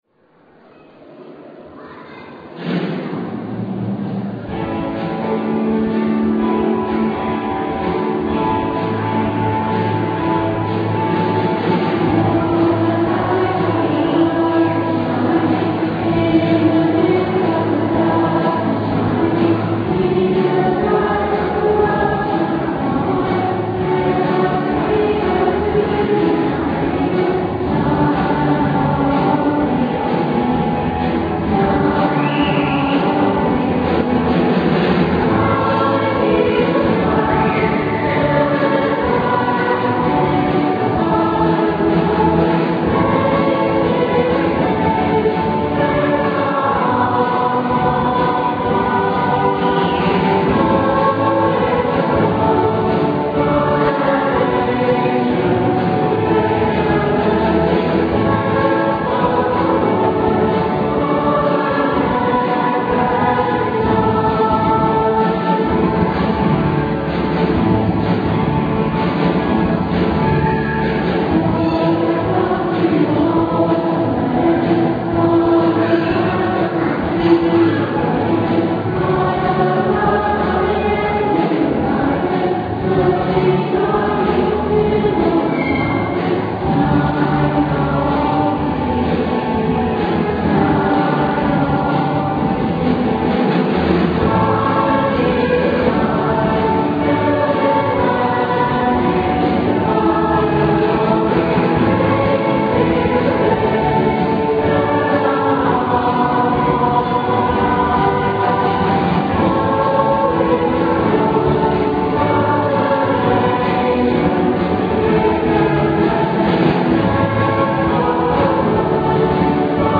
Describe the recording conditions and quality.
Recorded in digital stereo on minidisc at 10am Mass on 6th June 1999.